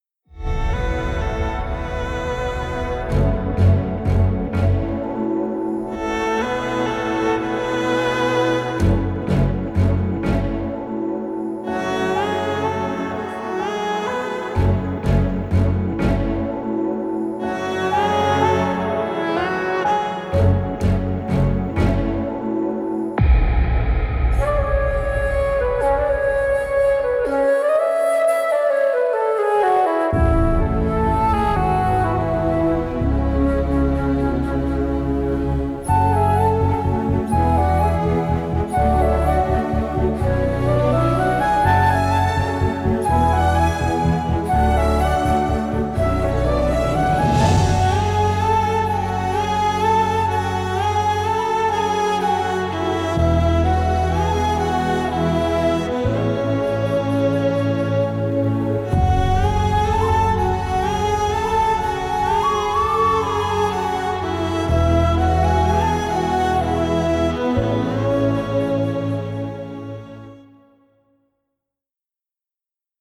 heart melting